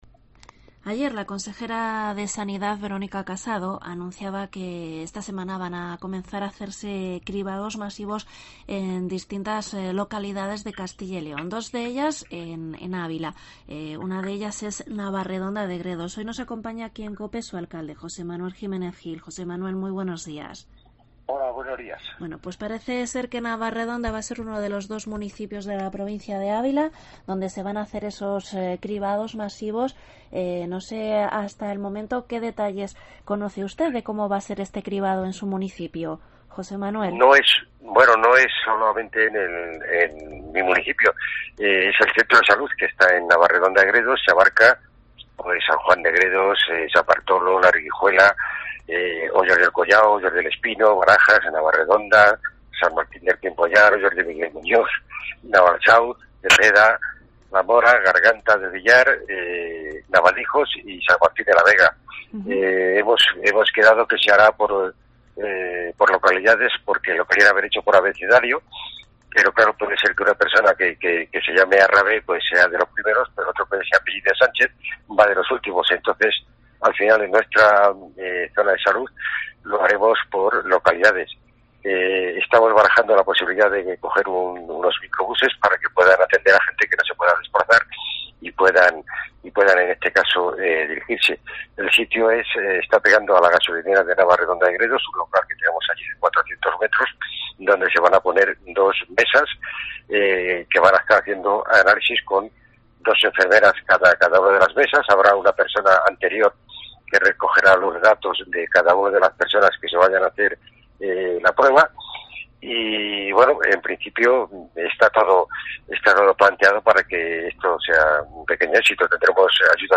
Entrevista alcalde Navarredonda de Gredos